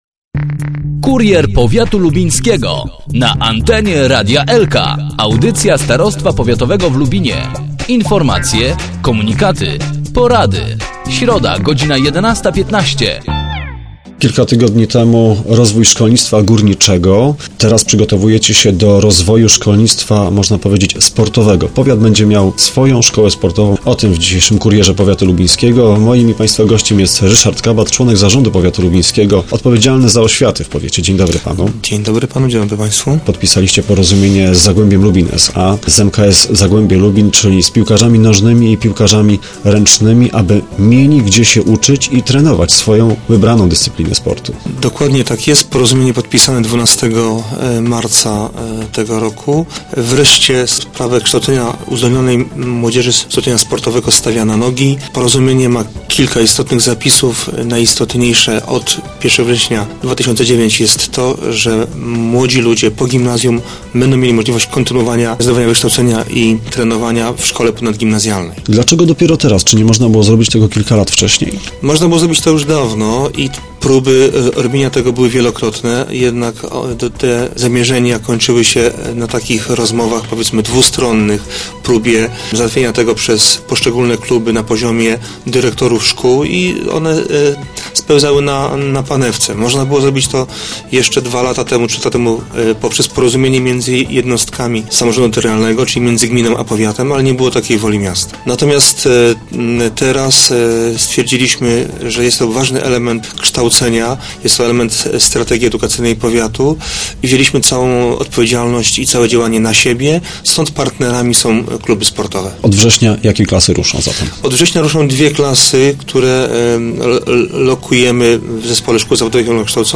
thumb_kabat.jpgLubin. Szkołę Mistrzostwa Sportowego chcą w Lubinie uruchomić władze powiatu. Od września w Zespole Szkół Zawodowych i Ogólnokształcących ruszą pierwsze w historii szkół średnich powiatu klasy kształcące piłkarzy ręcznych i nożnych. O szczegółach tego przedsięwzięcia w Kurierze Powiatu Lubińskiego rozmawialiśmy z Ryszardem Kabatem, członkiem zarządu powiatu odpowiedzialnym za oświatę.